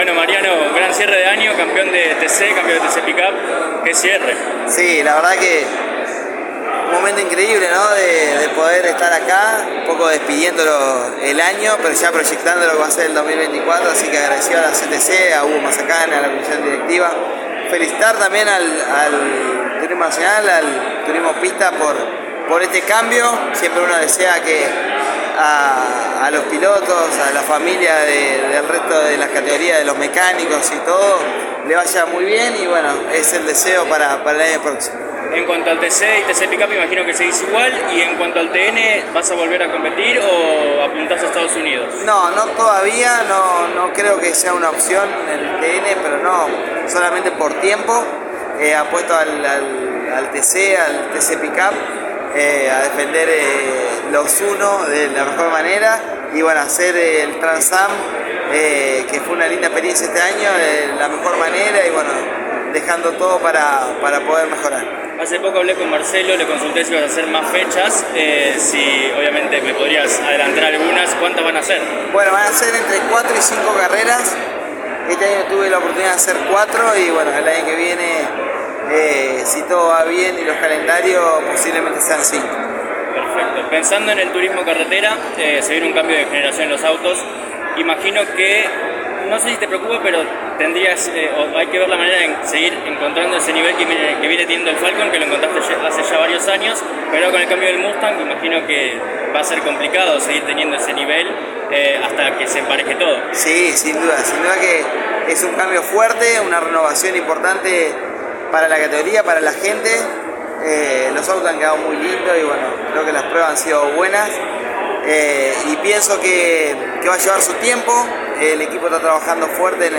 El tricampeón del Turismo Carretera se hizo presente en la sede de la ACTC para presencia el lanzamiento del calendario 2024 y habló de todo lo que vivió este año, como será su 2024 y de la alianza del Turismo Pista y el Turismo Nacional con la ACTC.